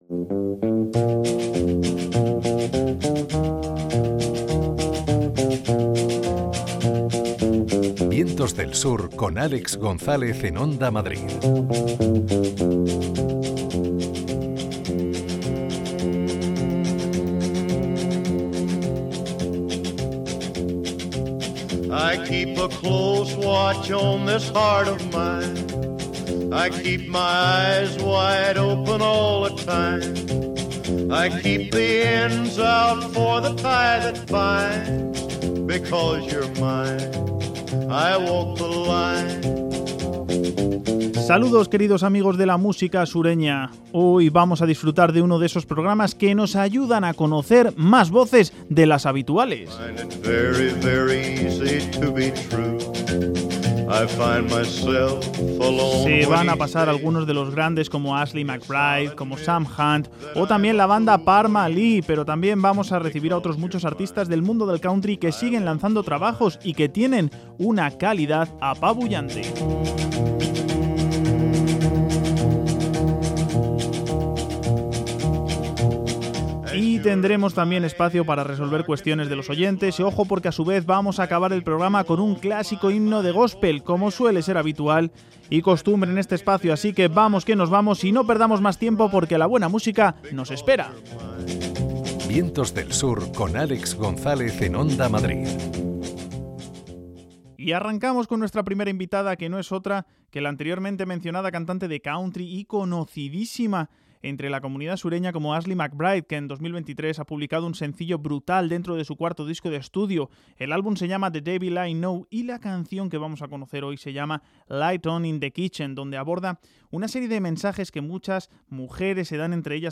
Programa repleto de grandes voces y otras nuevas del mundo del country.